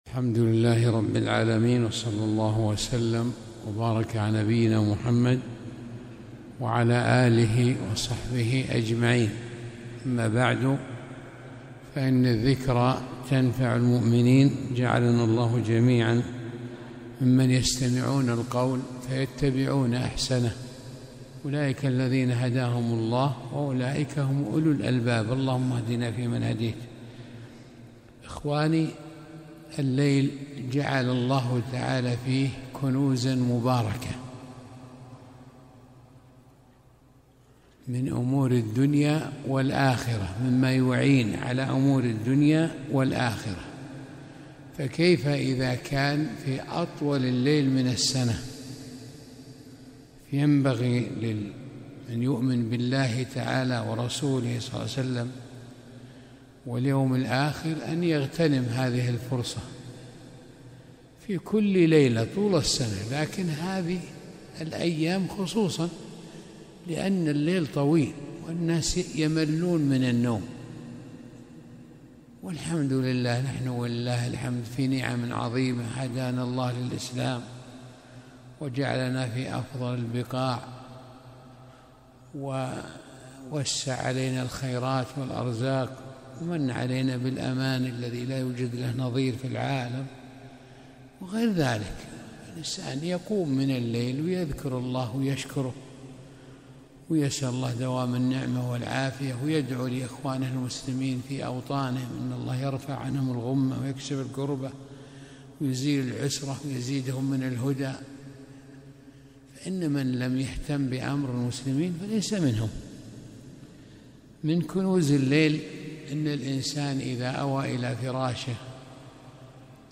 موعظة: تذكرة بشيء من كنوز الليل